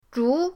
zhu2.mp3